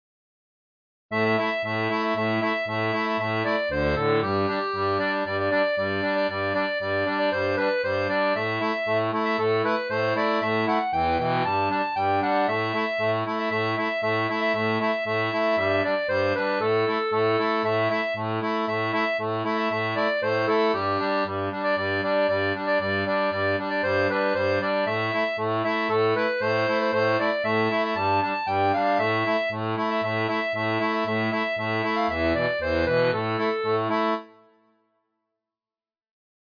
Chant de marins